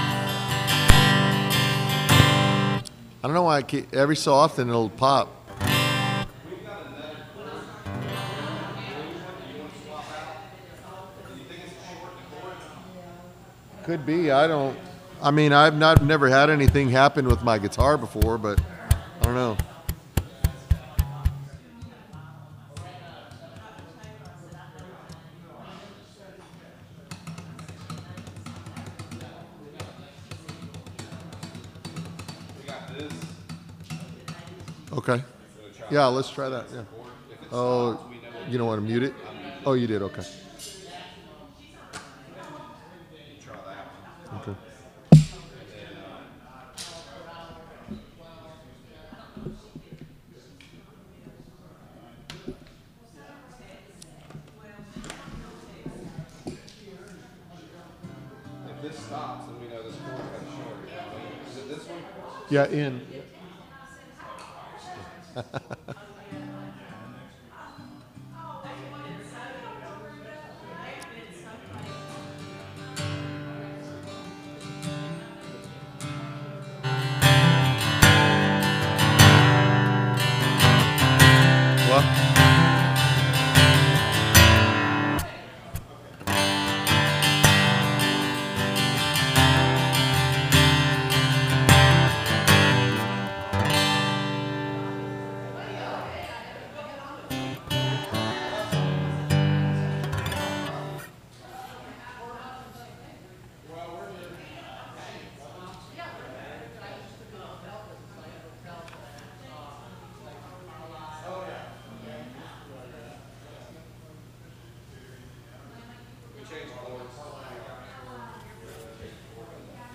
SERMON DESCRIPTION In Hosea, God reveals Himself as both Judge and Healer.